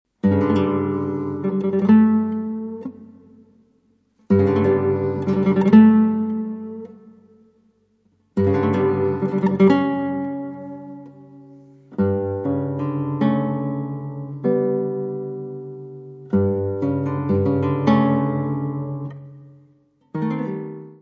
piano
guitarra.
Área:  Música Clássica